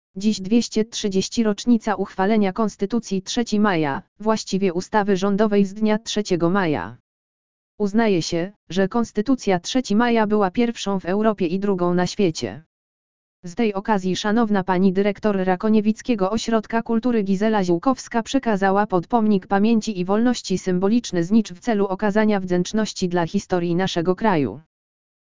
audio_lektor_swieto_konstytucji_3_maja.mp3